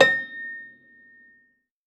53w-pno12-A4.wav